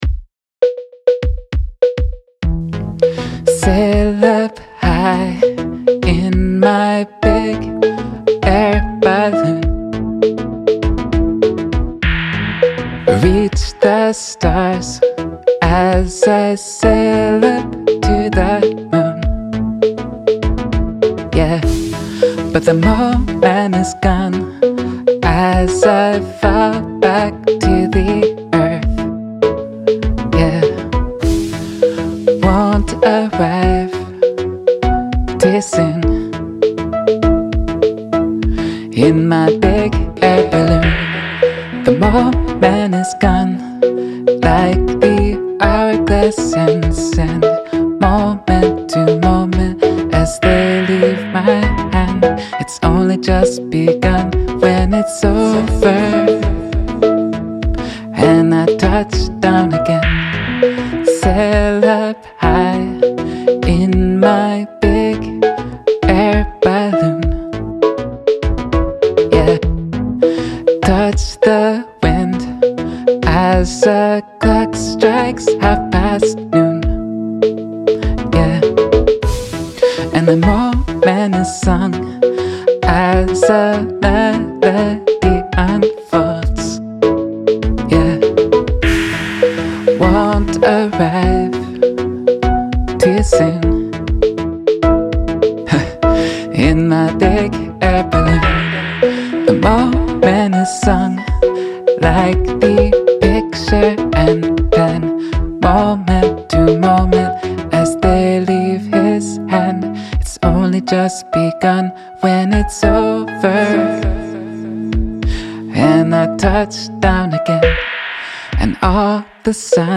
11 Air Balloon Electronic.mp3